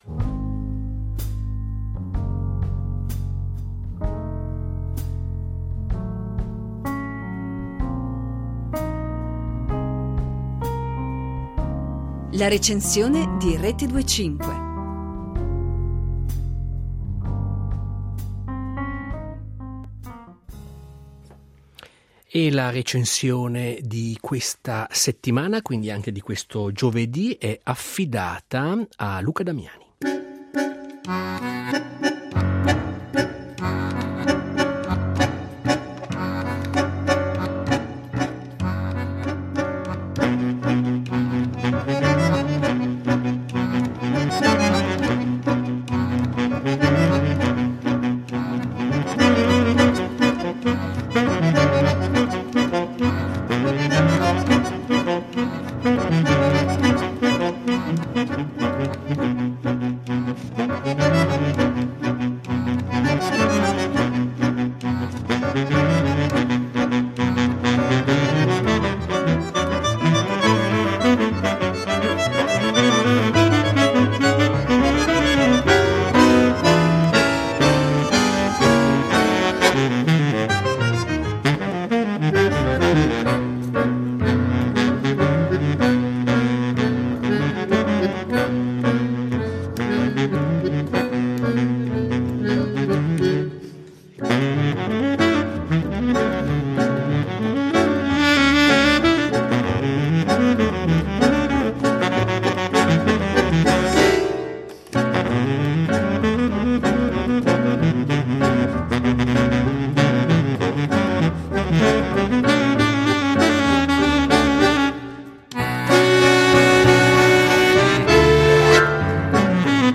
bandoneon
pianoforte